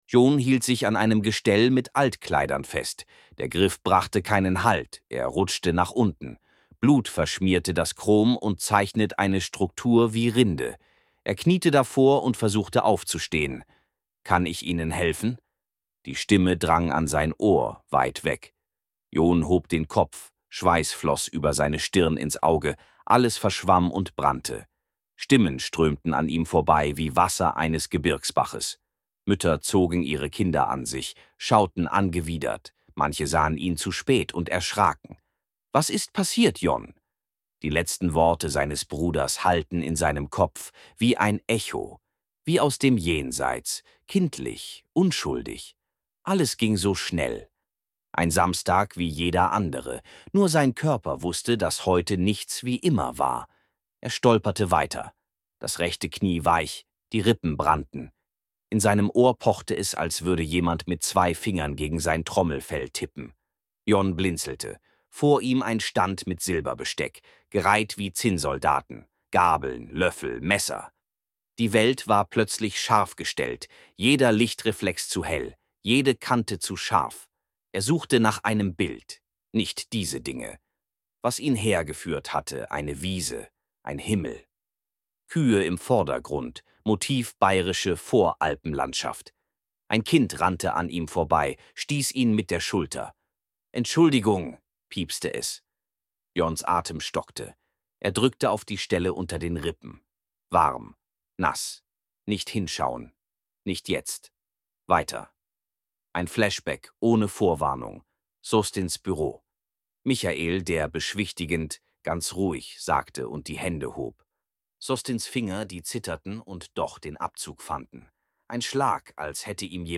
Hier Mal eine kleine Probe von meinem neuen Projekt Die Stimmen sind noch sehr rudimentär, aber ich glaube, das bekomme ich noch rund.